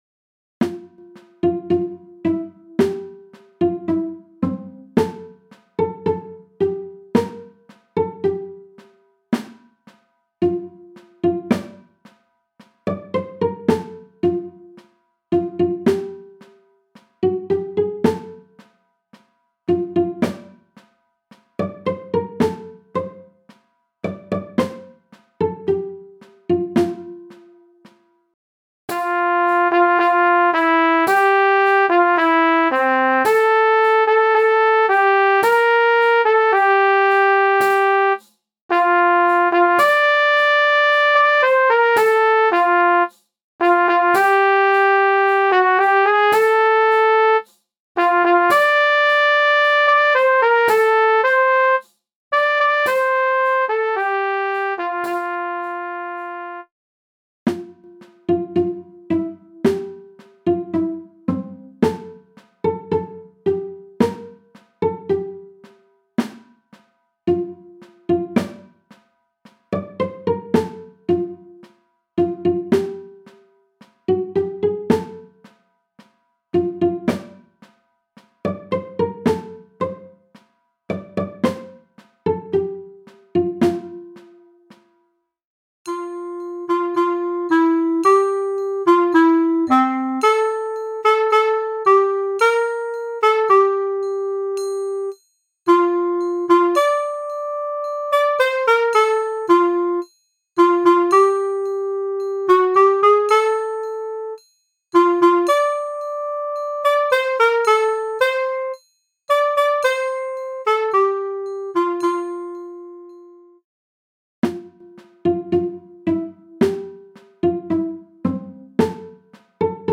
MIDI von 2011 [5.694 KB] - mp3
midi_der-romfahrer_f-dur_1-stimmig_320.mp3